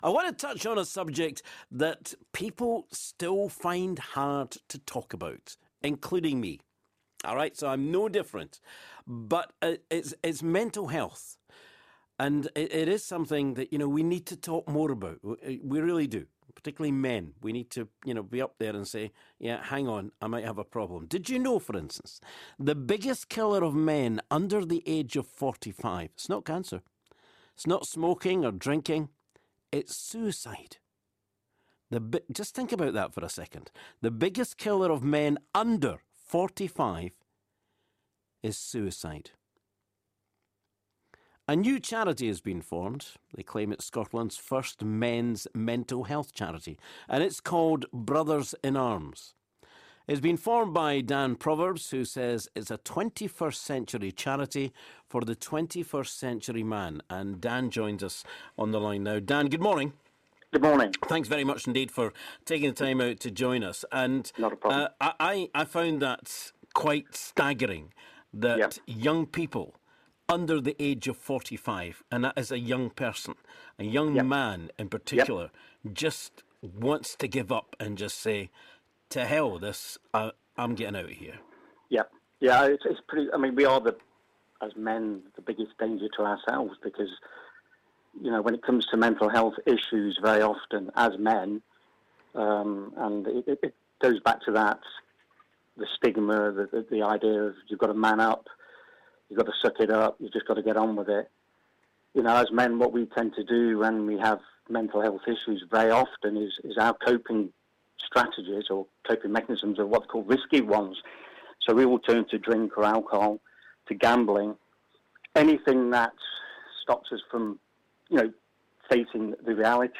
spoke to callers into the show who spoke movingly about their own experiences of suicide.